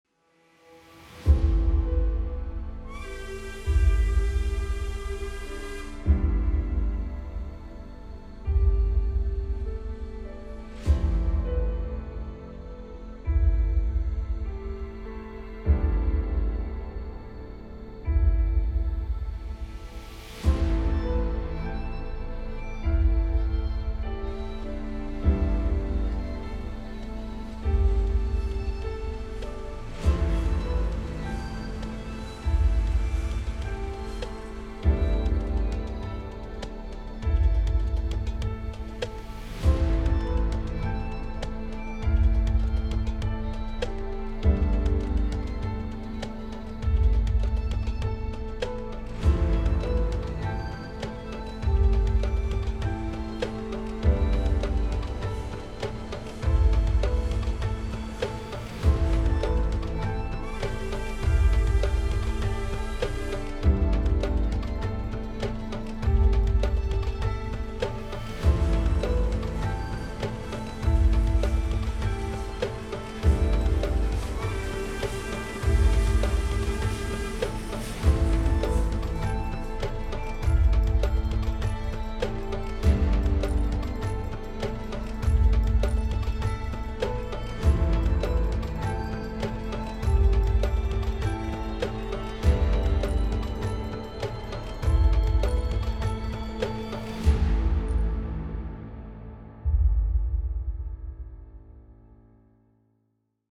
On the occasion of the 100th anniversary of Colombo Badulla railway service, the special steam train that ran from Nanuyo to Badulla passing the famous Demodara rail junction.